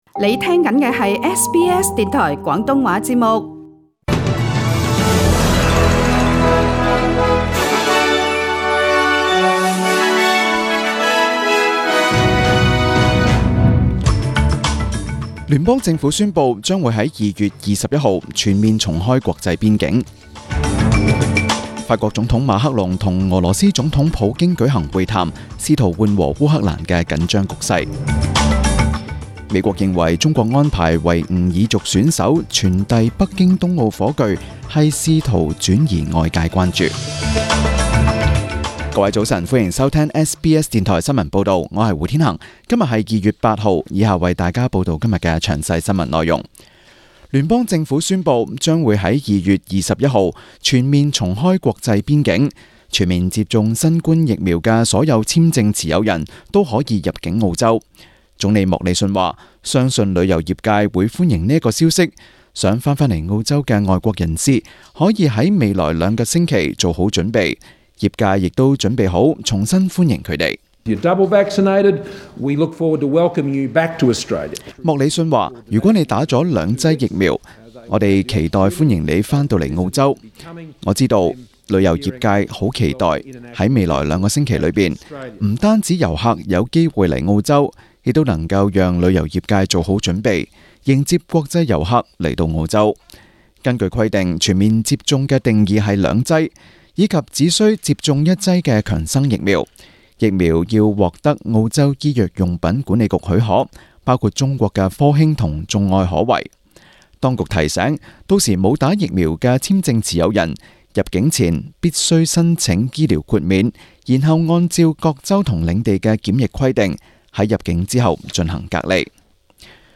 中文新聞 （2月8日）